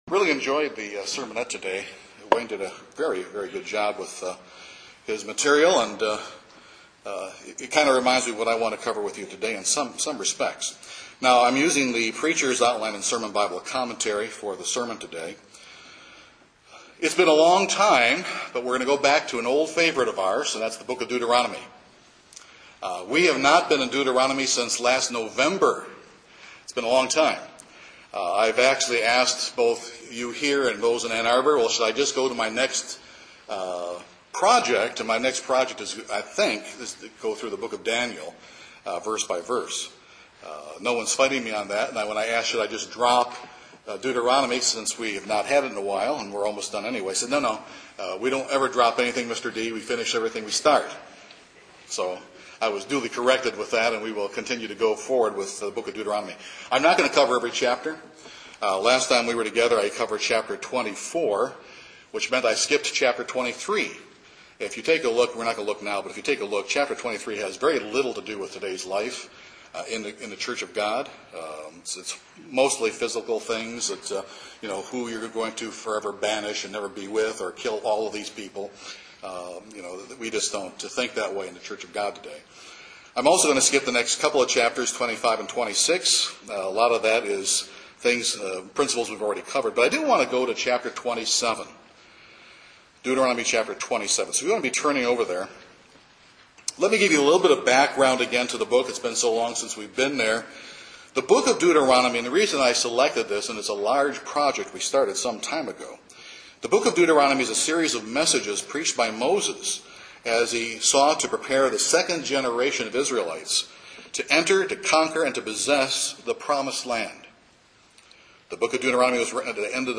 Prior to entering the Promised Land Moses was inspired to preach a series of sermons that challenged the Israelites to recommit and rededicate their lives to God. This is the first such sermon in this series. Today, as we are poised to enter the Kingdom of God, are we recommitting and rededicating our lives to God?